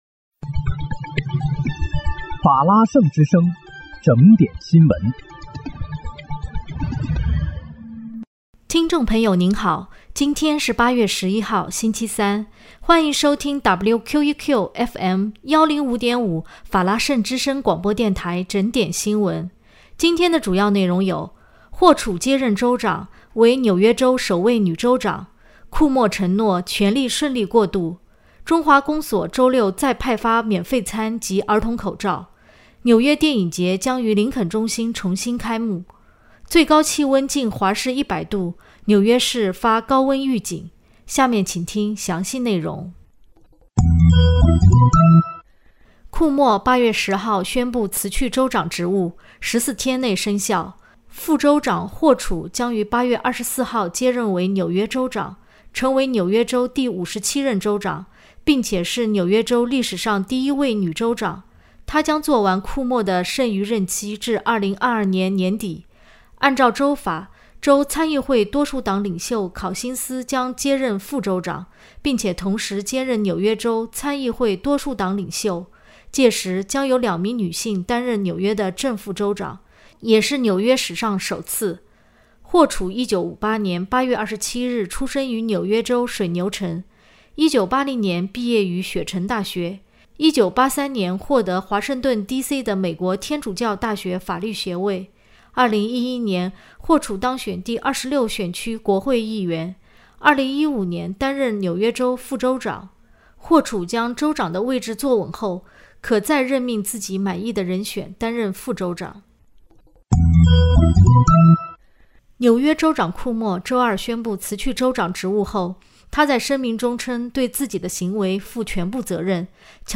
8月11日（星期三）紐約整點新聞